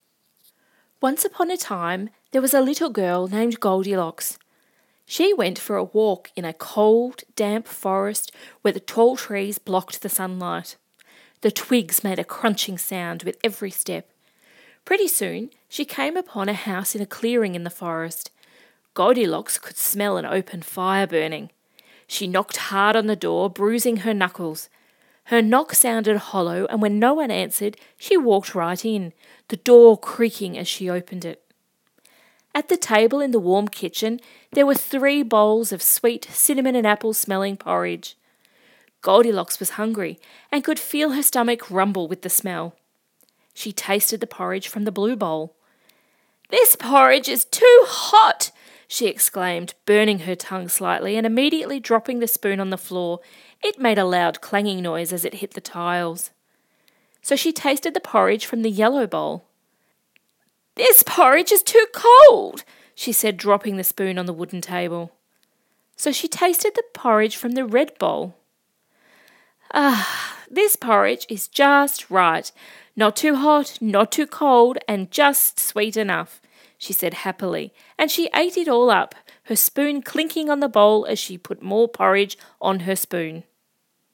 Let’s work through a short example…..it’s a rudimentary example using the familiar story of Goldilocks and the Three Bears.